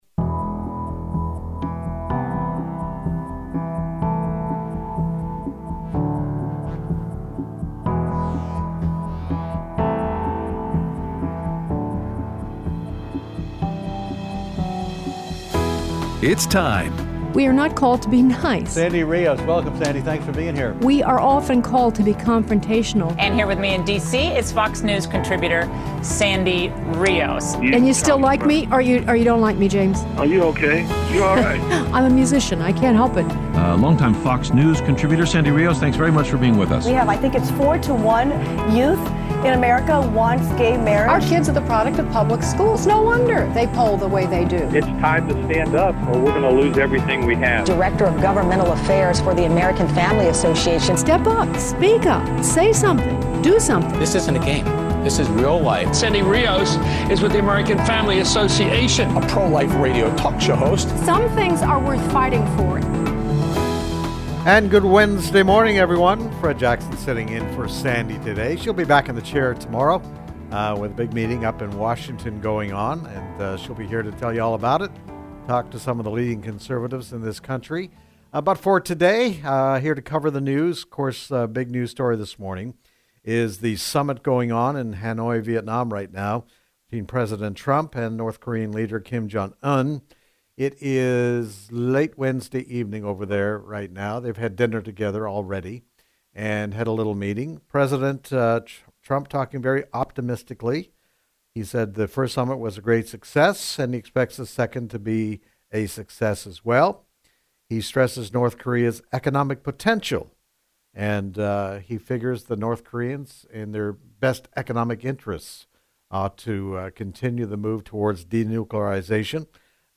Guest Host
interviews Gary Bauer on Several Topics, and Takes Your Phone Calls